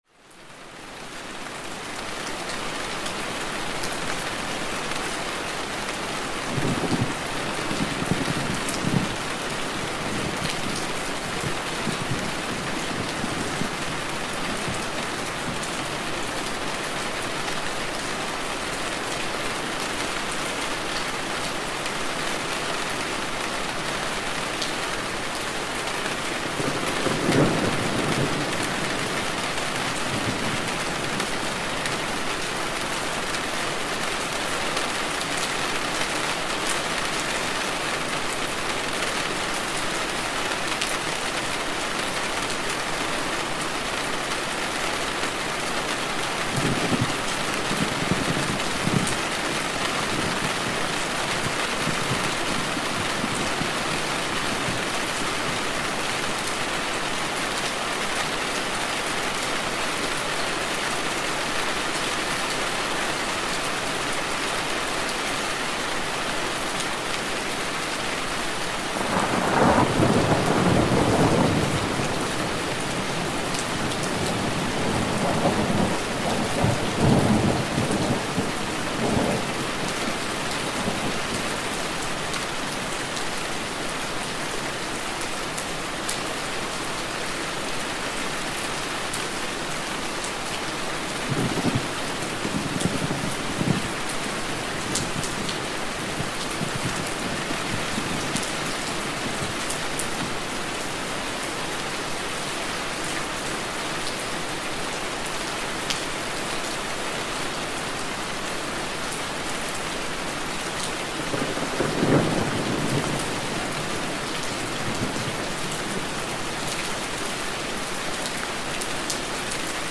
Rainstorm for Relaxation – 1 Hour Deep Sleep Sound